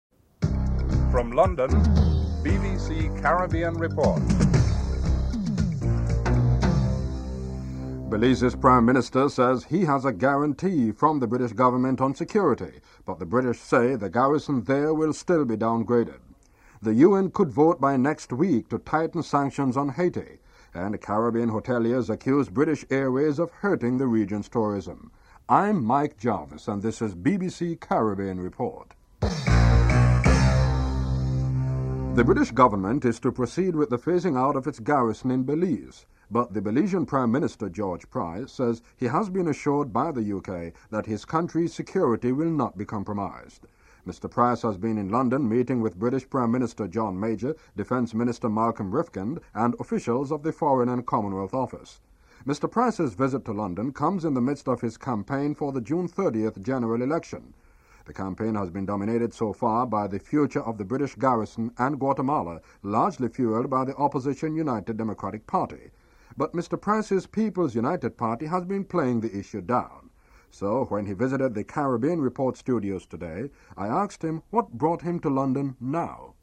1. Headlines (00:00-00:40)
Interview with George Price, Prime Minister of Belize (00:41-05:32)